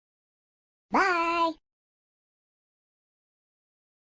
Звуки бай-бай